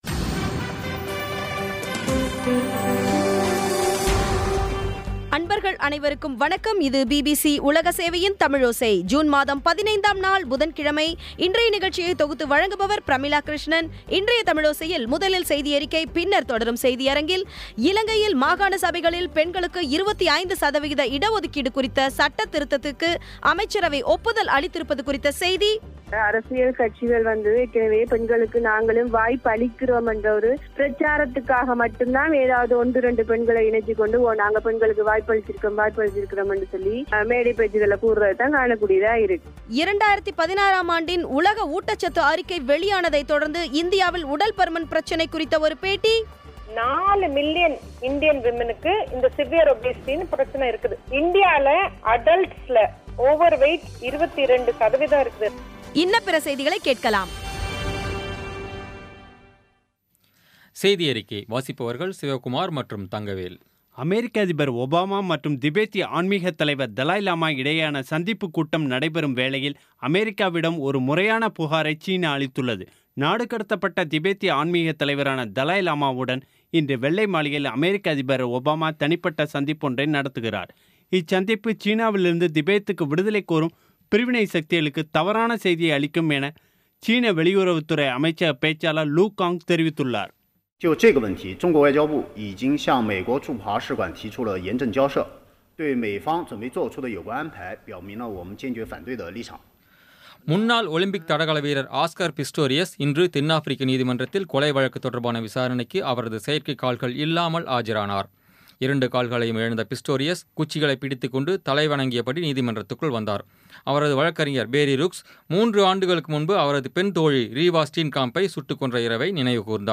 இன்றைய தமிழோசையில், முதலில் செய்தியறிக்கை பின்னர் தொடரும் செய்தியரங்கில், இலங்கையில் மாகாண சபைகளில் பெண்களுக்கு 25 சத வீத இட ஒதுக்கீடு கிடைக்கும் வகையில் கொண்டுவரப்படவுள்ள சட்டத் திருத்தத்துக்கு அமைச்சரவை ஒப்புதலலளித்திருப்பது குறித்த செய்தி 2016ம் ஆண்டின் உலக ஊட்டச்சத்து அறிக்கை வெளியானதை தொடர்ந்து இந்தியாவில் உடற்பருமன் பிரச்சனை குறித்த ஒரு பேட்டி இன்ன பிற செய்திகளை கேட்கலாம்..